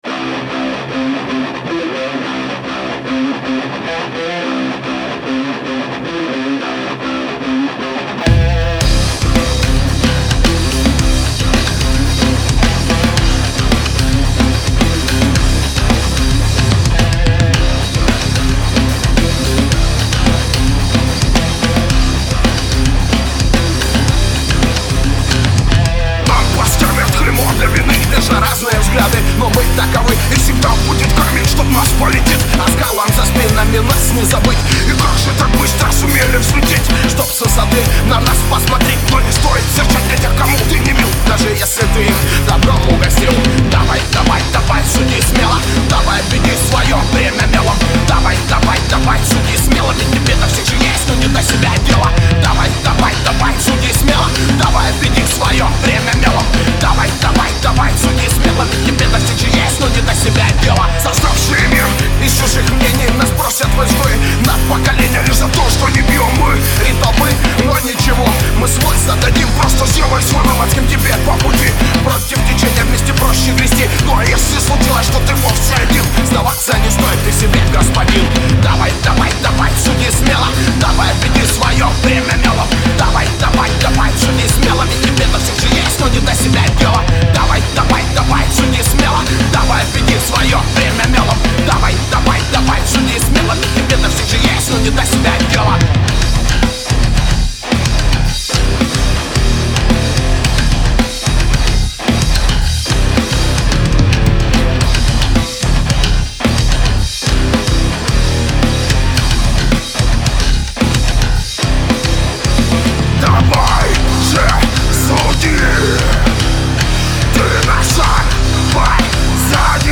"Вытащил" гитарку и подправил по мелочи) (второй файл)